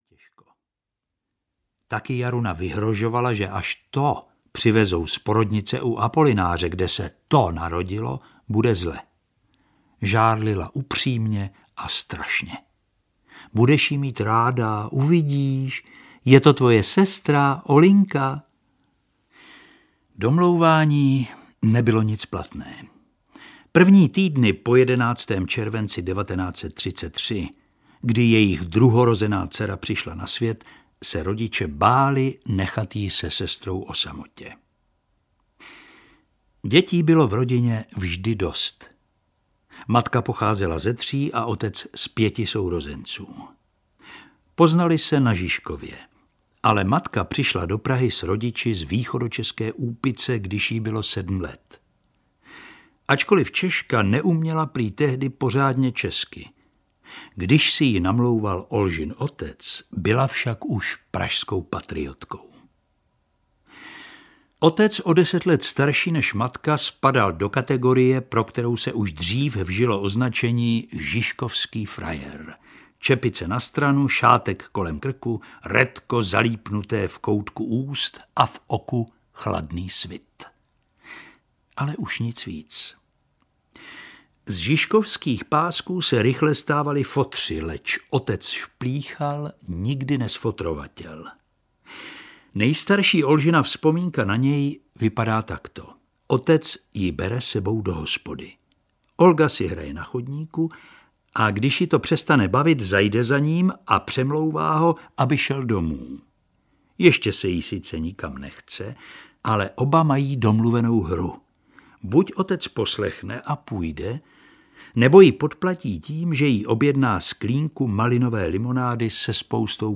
Audio kniha
Ukázka z knihy
• InterpretJiří Ornest